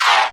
pbs - skrrt [ Perc ].wav